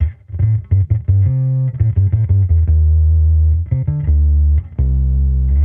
Index of /musicradar/sampled-funk-soul-samples/85bpm/Bass
SSF_PBassProc2_85A.wav